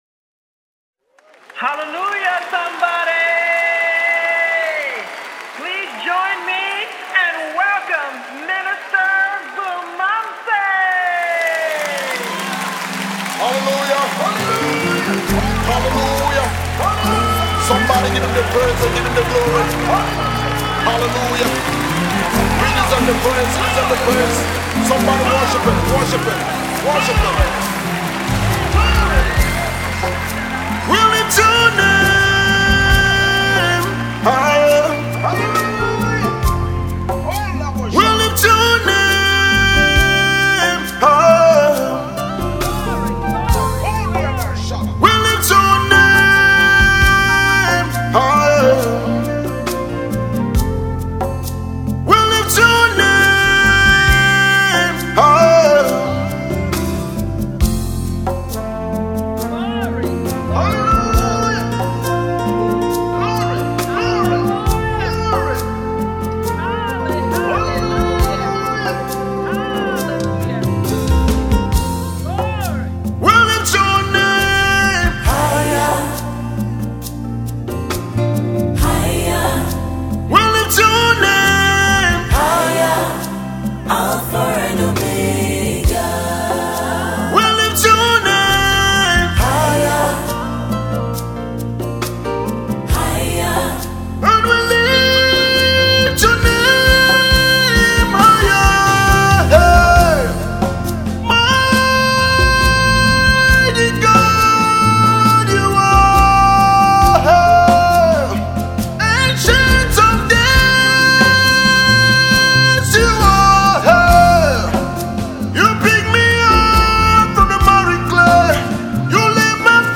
Cameroonian gospel musician
gospel music